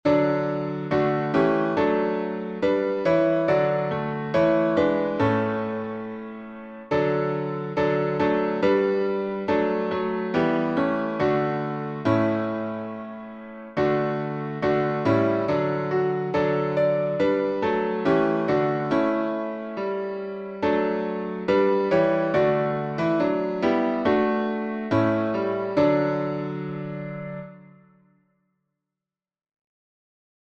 #52: Jesus Shall Reign — D Major — Duke Street | Mobile Hymns